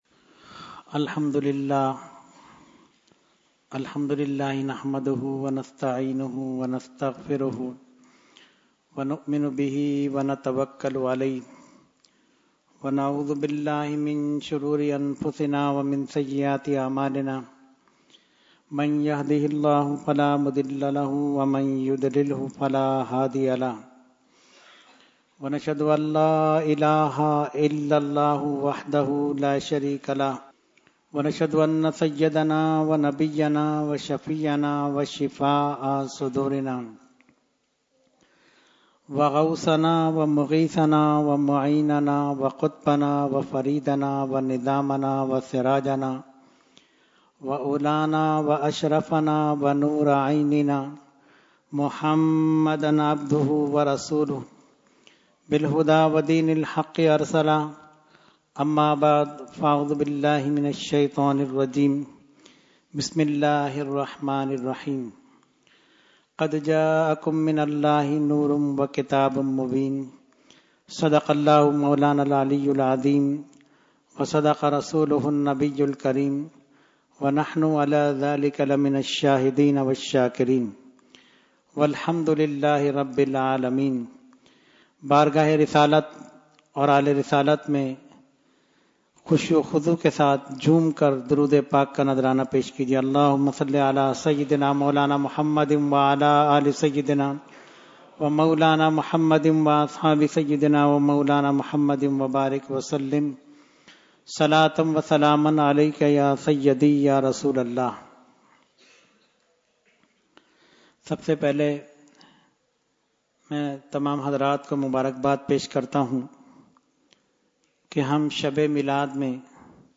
Weekly Tarbiyati Nashist held on 2/3/2014 at Dargah Alia Ashrafia Ashrafabad Firdous Colony Karachi.
Category : Speech | Language : UrduEvent : Jashne Subah Baharan 2017